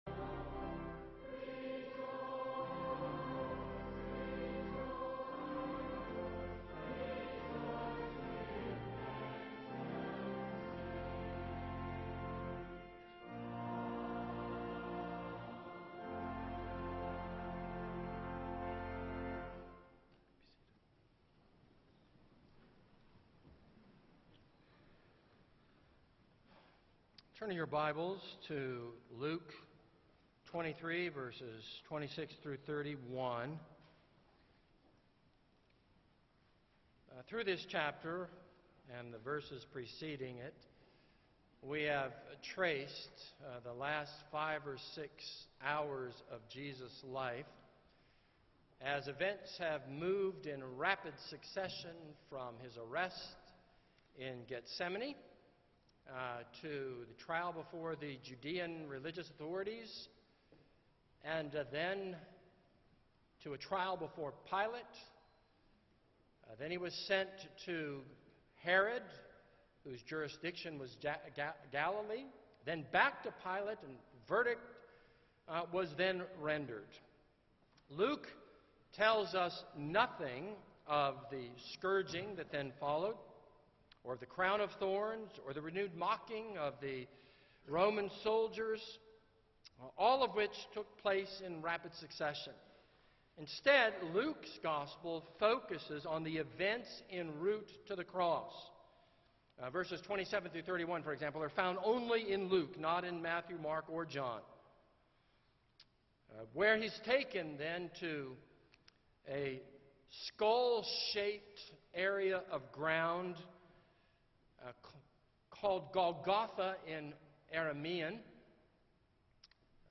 This is a sermon on Luke 23:26-31.